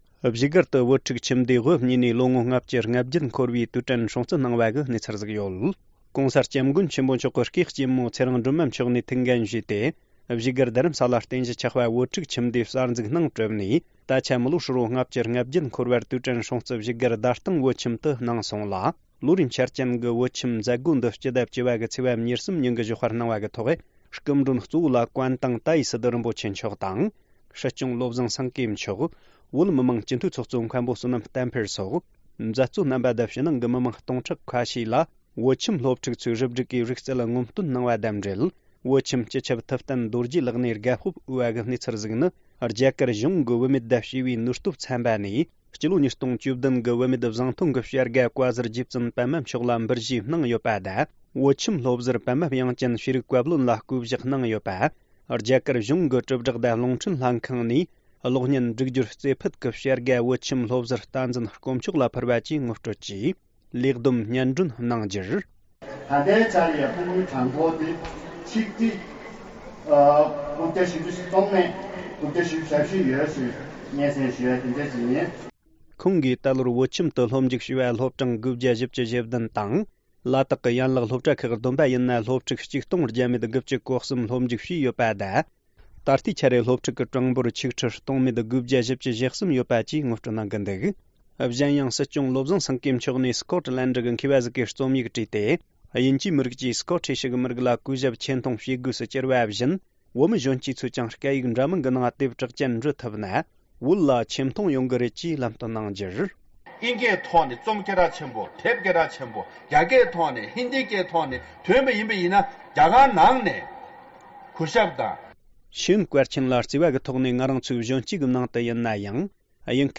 གནས་ཚུལ